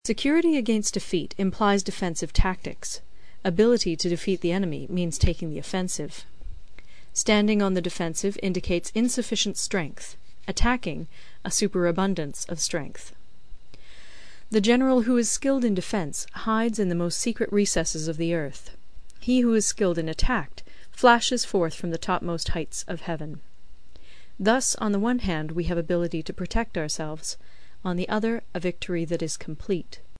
有声读物《孙子兵法》第21期:第四章 军形(2) 听力文件下载—在线英语听力室